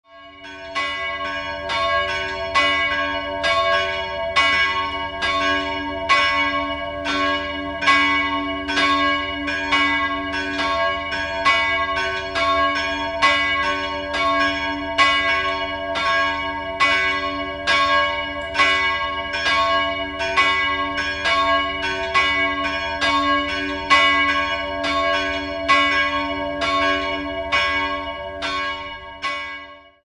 Der Hochaltar mit den Akanthusranken stammt aus dem Jahr 1730. 2-stimmiges Geläute: cis''-fis'' Die größere Glocke wurde vermutlich 1738 von Neumair (Stadtamhof) gegossen, die kleinere könnte noch von 1446 stammen.